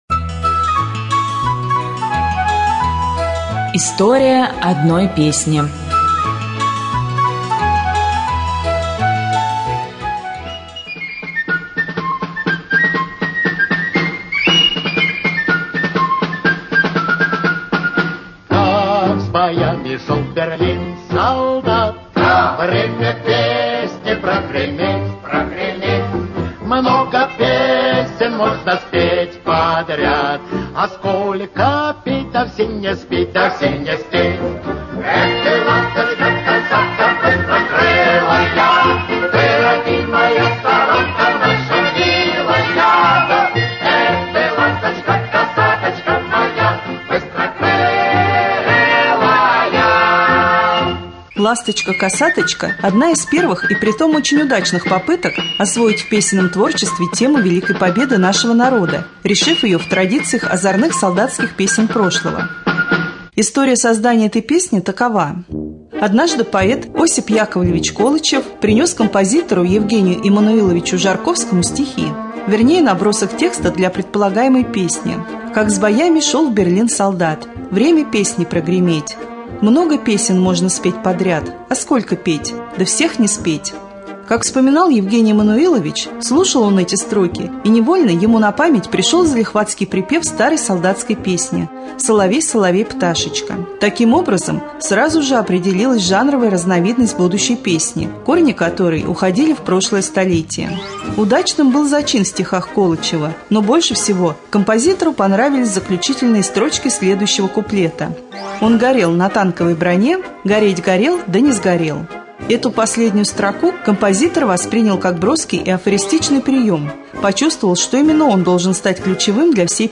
23.04.2013г. в эфире раменского радио - РамМедиа - Раменский муниципальный округ - Раменское